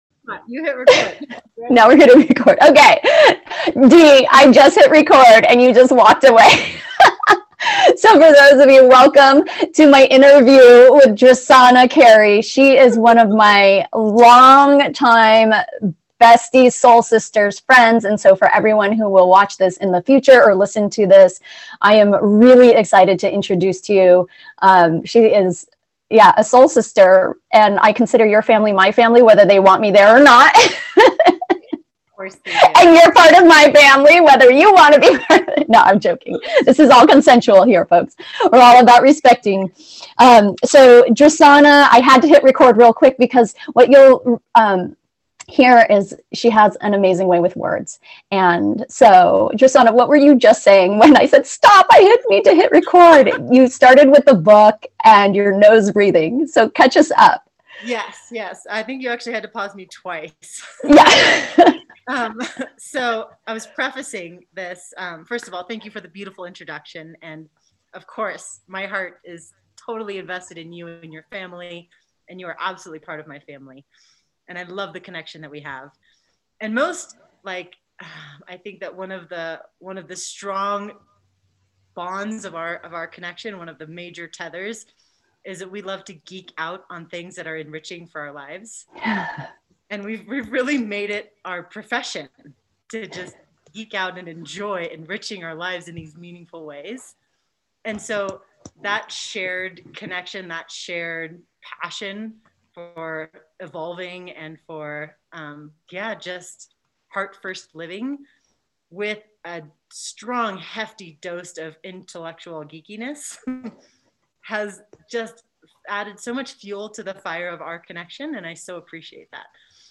Listen to the AUDIO only version of my conversation with my bestie